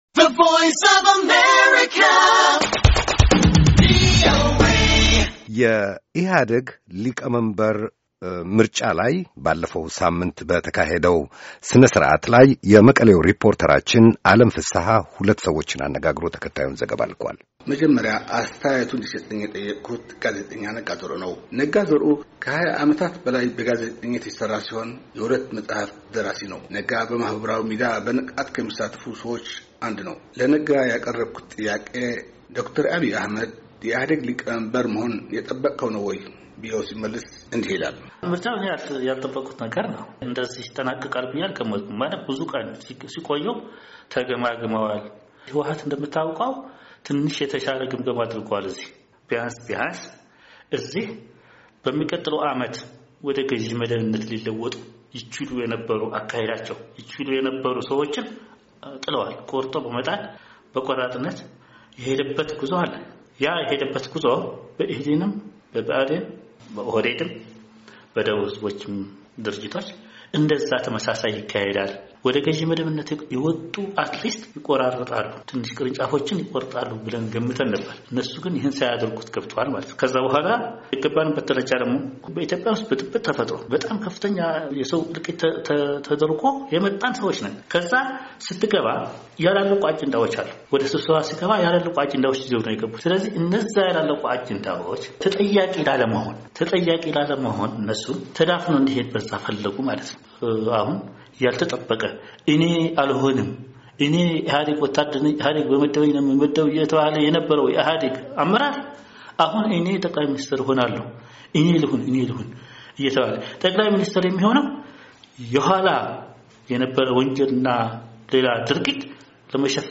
ሁለት የመቀሌ ነዋሪዎች በዶ/ር አብይ የኢህአዴግ ሊቀመንበርነት ምርጫ ላይ የሰጡት አስተያየት